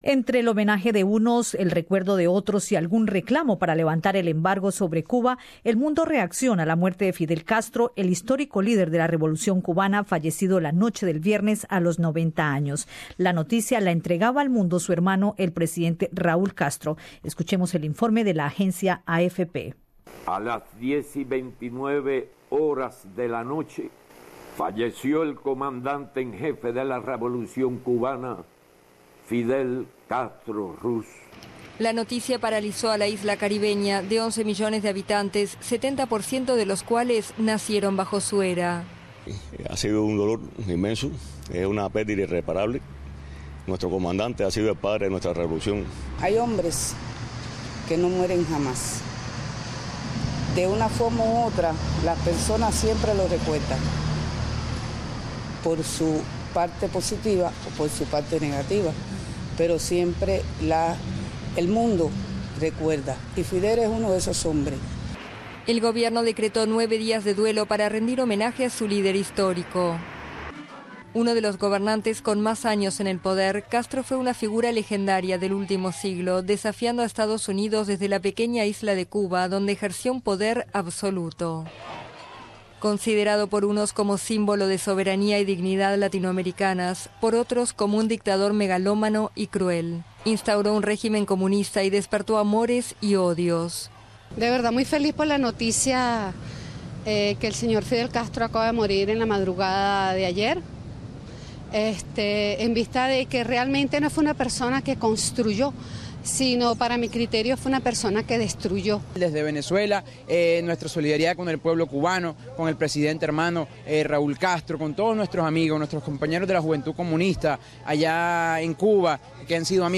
El boletín de noticias de Radio SBS trae algunas reacciones expresadas en distintos lugares del mundo, tras la muerte del histórico líder cubano, acaecida este viernes, a sus 90 años.